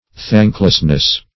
Thank"less*ness, n.